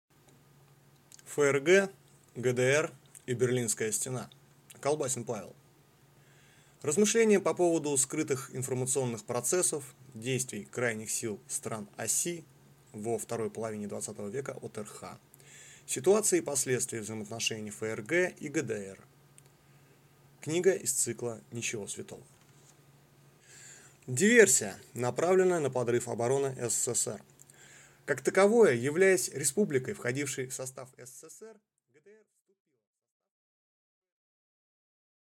Аудиокнига ФРГ, ГДР и Берлинская стена | Библиотека аудиокниг
Прослушать и бесплатно скачать фрагмент аудиокниги